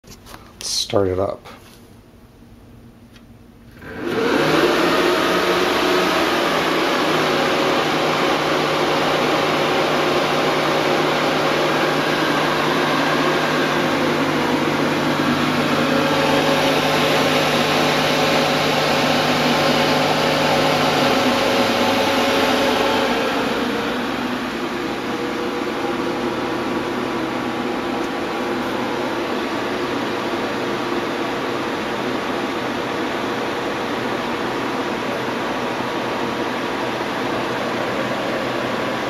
NoiseII.mp3